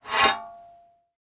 shovelshing.ogg